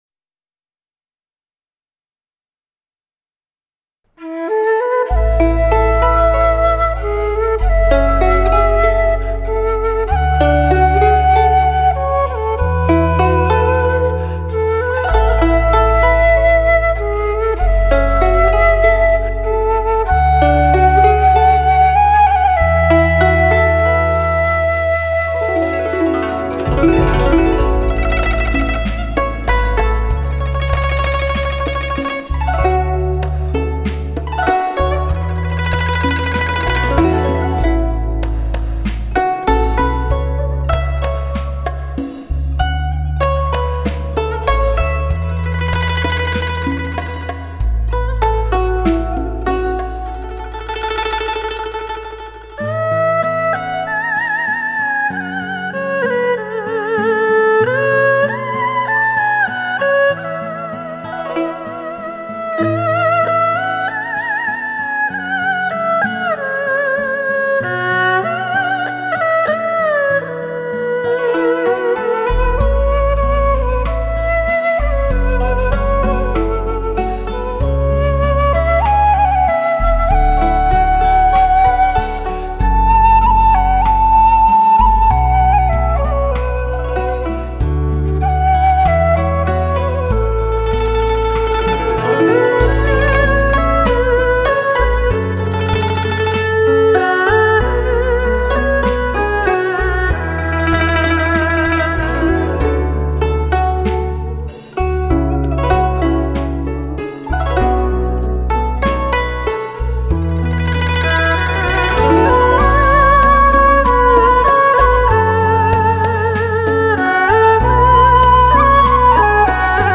类型: HIFI试音
温馨提示：日本JVC特别低音处理，试听时请注意调节BASS（低音）。